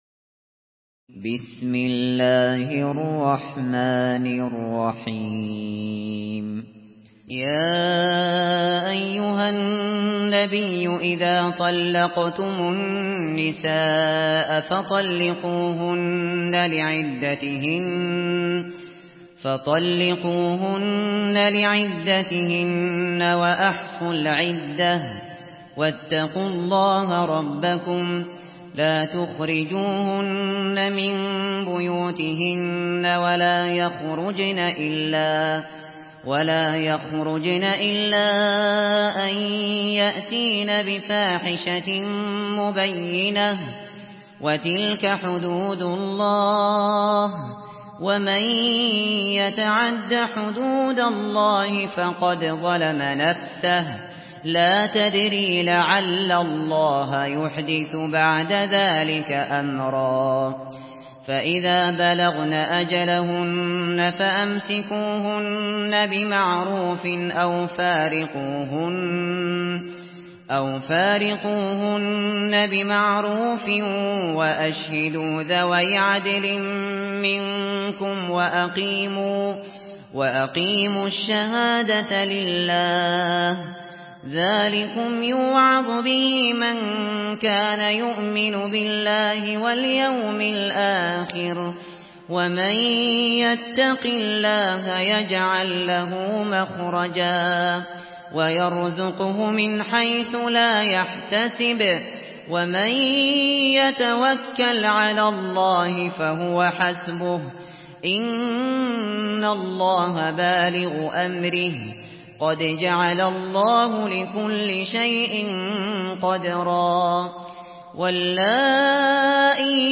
سورة الطلاق MP3 بصوت أبو بكر الشاطري برواية حفص
مرتل